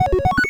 retro_beeps_success_05.wav